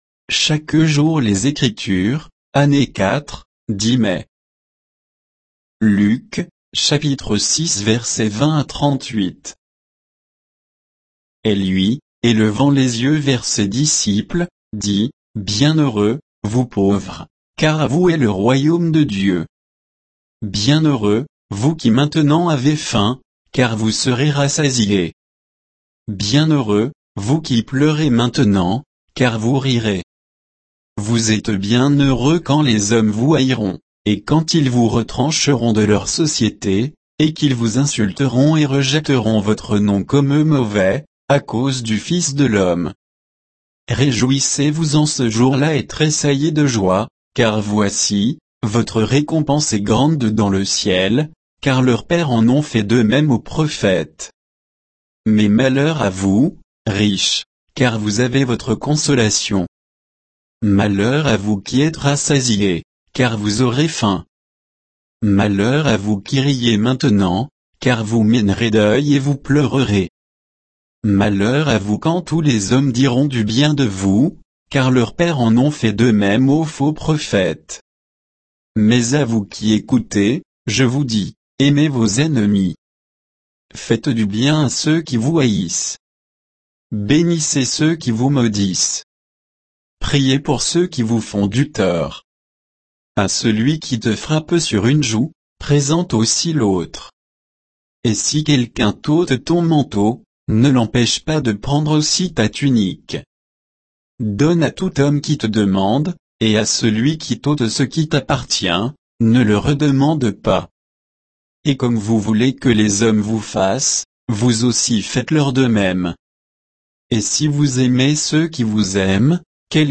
Méditation quoditienne de Chaque jour les Écritures sur Luc 6